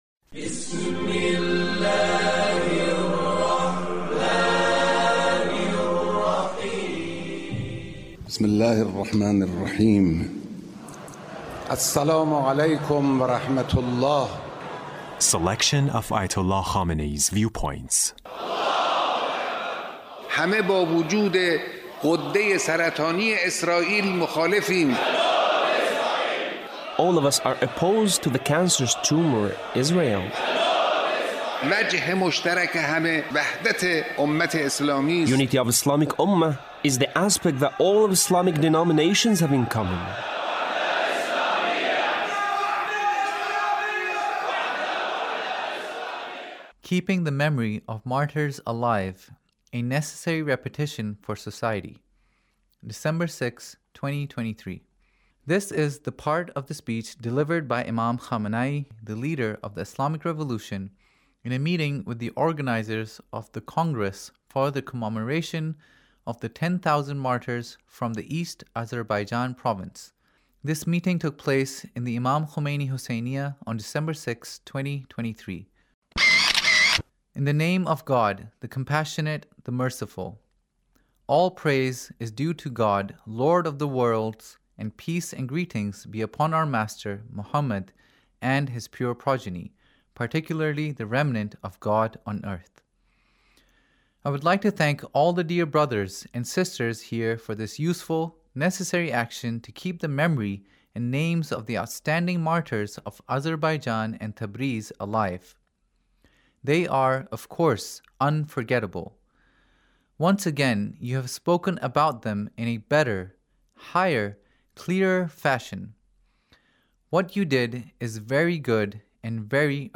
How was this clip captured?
Leader's Speech in a meeting with the organizers of the Congress for the Commemoration of the 10,000 Martyrs from the East Azerbaijan Province